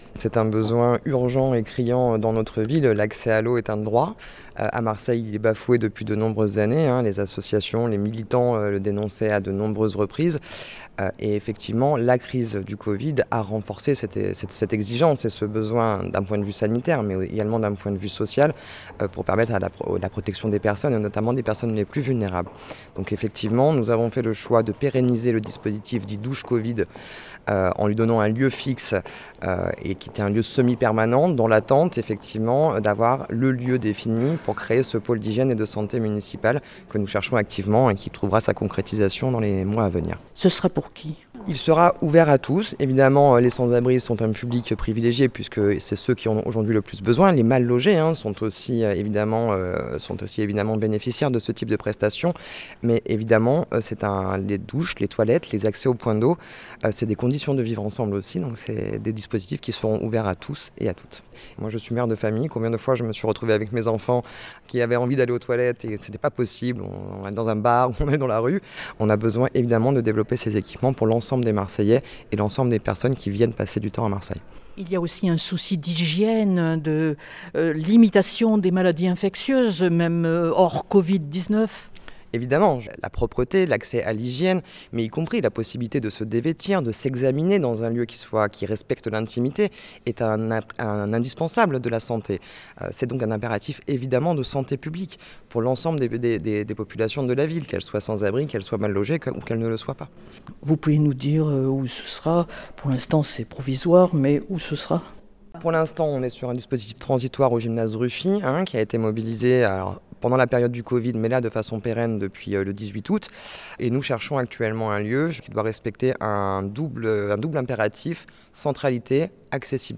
Elle indique à ce propos que 16 000 personnes on été recensées sans abri à Marseille en 2019. Entretien.